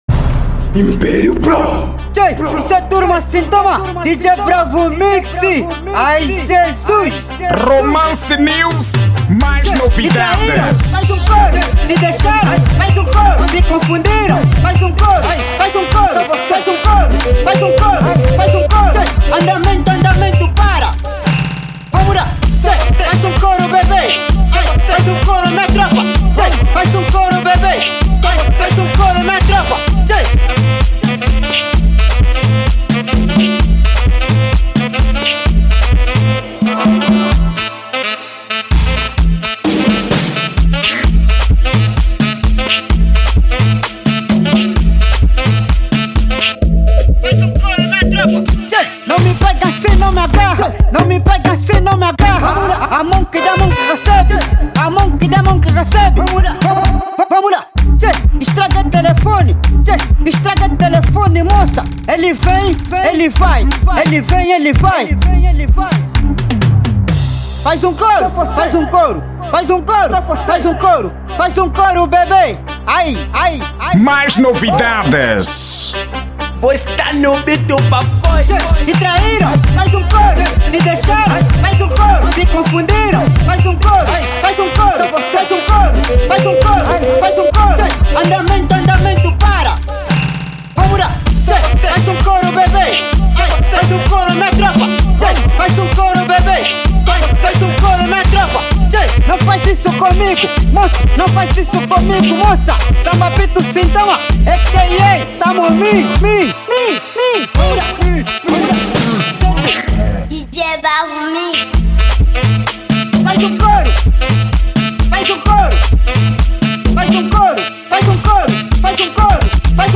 Estilo: Afro House